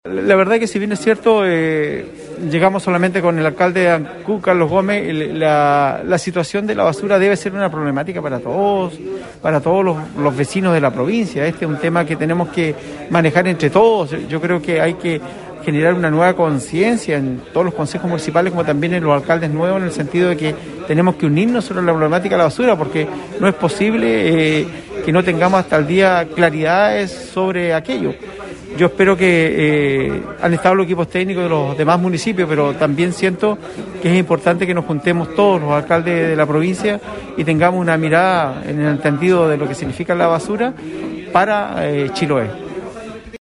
Puntualmente acerca de esa falta de interés, el jefe comunal de Achao dijo que era imposible avanzar en soluciones para la crisis de la basura sin la participación de los gobiernos comunales.
11-ALCALDE-QUINCHAO.mp3